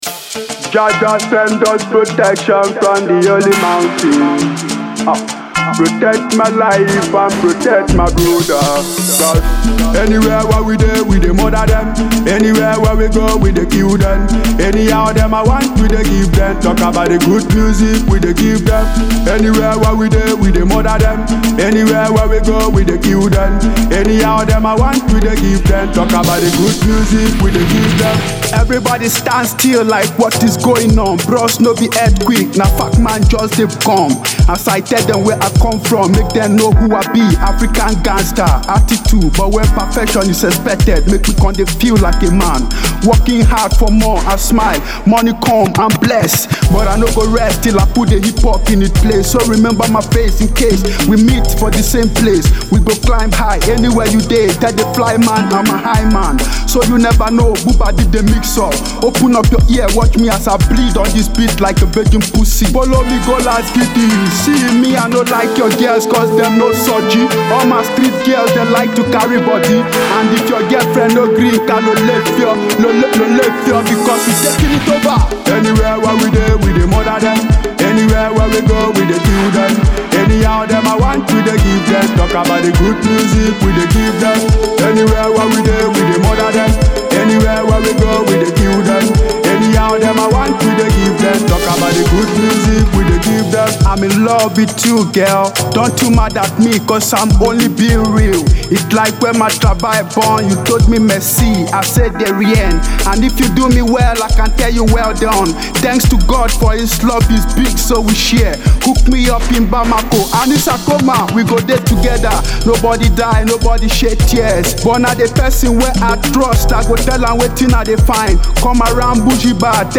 The Fast Rising Hip Hop Artist